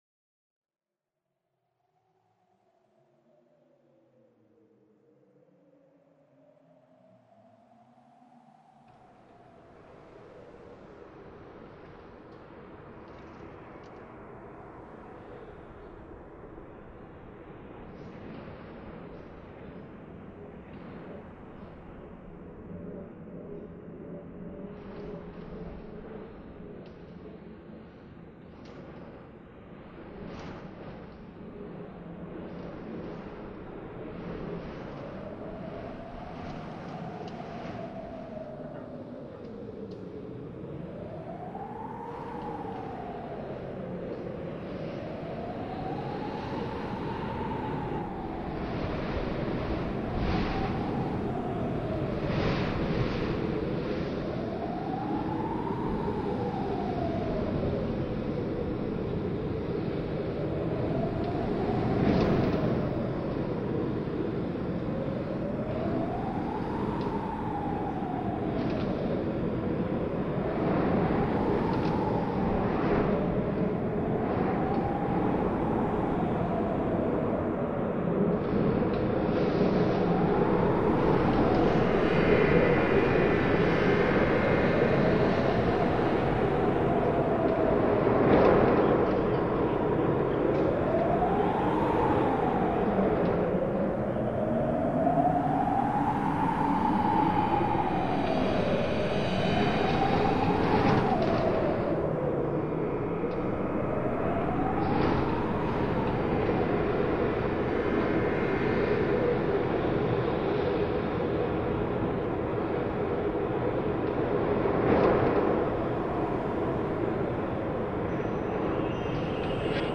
File under: Experimental / Industrial / Noise